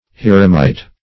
Search Result for " heremite" : The Collaborative International Dictionary of English v.0.48: Heremit \Her"e*mit\, Heremite \Her"e*mite\, n. [See Hermit .]
heremite.mp3